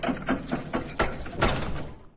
Door2Open1.ogg